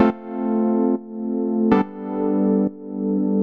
Index of /musicradar/sidechained-samples/140bpm
GnS_Pad-alesis1:2_140-A.wav